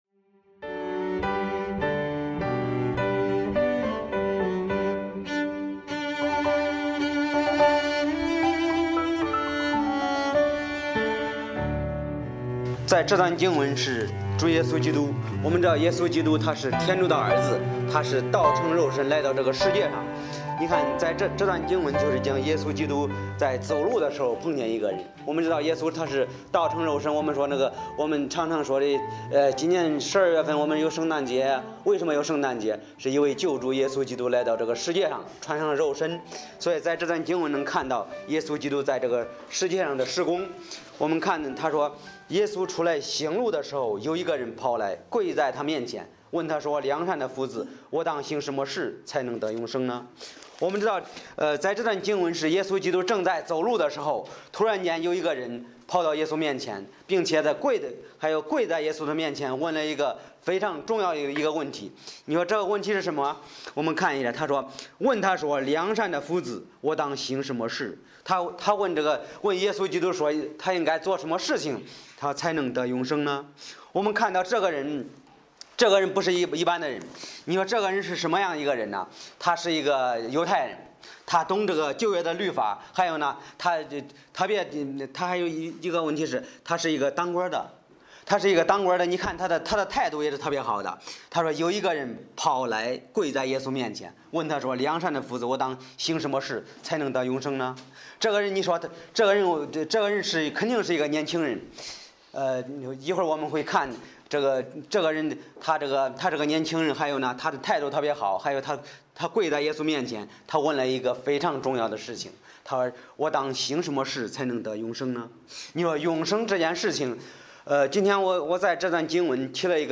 Bible Text: 马可福音10：17-22 | 讲道者